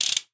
Index of /90_sSampleCDs/Best Service ProSamples vol.42 - Session Instruments [AIFF, EXS24, HALion, WAV] 1CD/PS-42 AIFF Session Instruments/Percussion